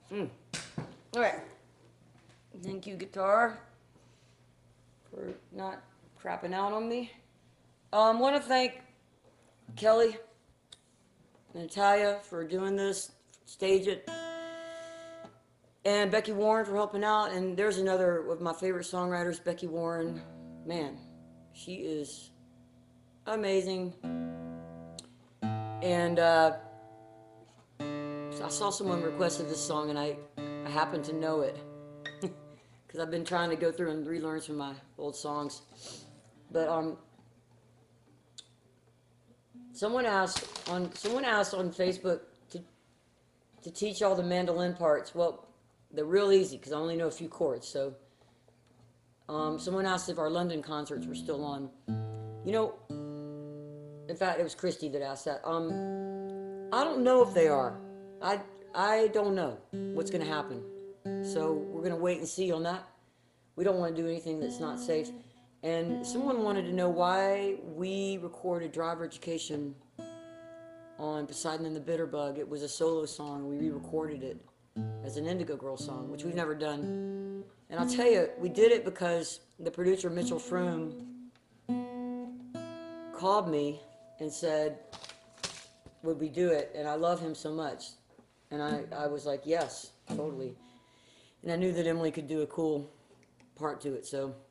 (captured from the live video stream)
16. talking with the crowd (1:34)